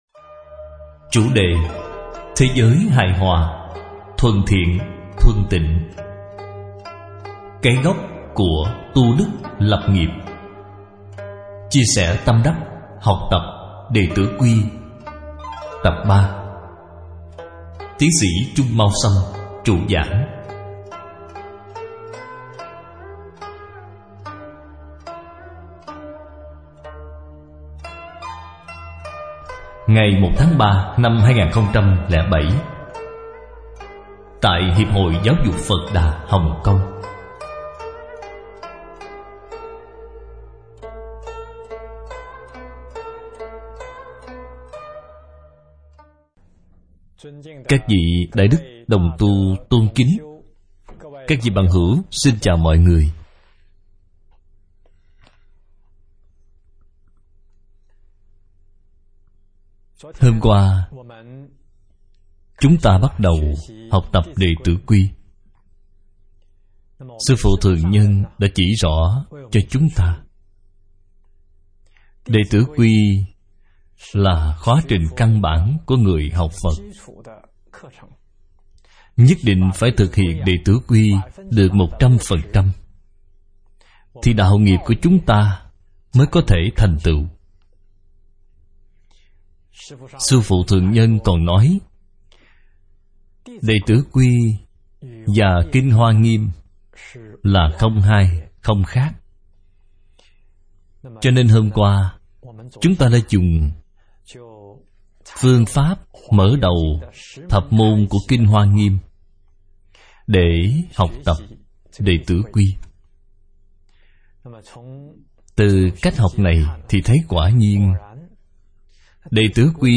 Tâm Đắc Học Tập Đệ Tử Quy - Bài giảng Video